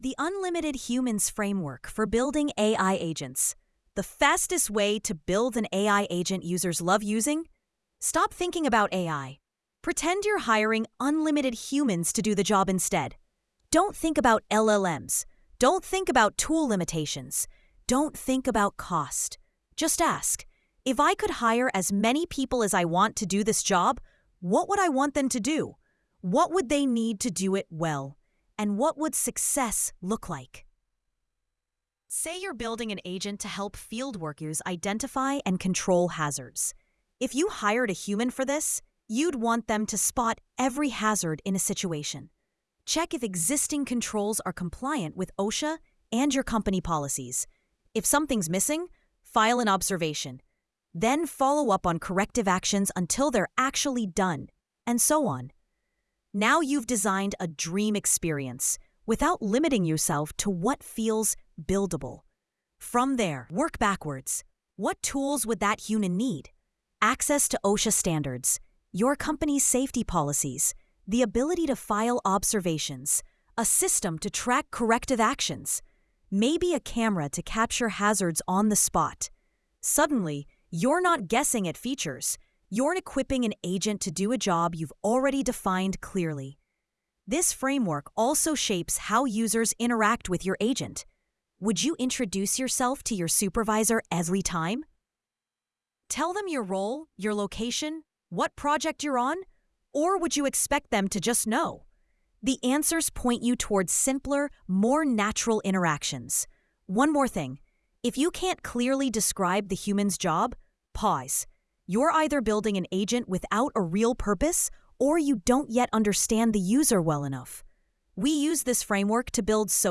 sage_gpt-4o-mini-tts_1x_2025-12-25T18_36_54-305Z.wav